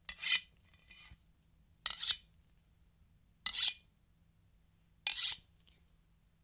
metallic tin hits
描述：Recorded with Zoom H5 SGH6 shotgun mic.
标签： H5 cardboard tin metallic Zoom tube hits SGH6
声道立体声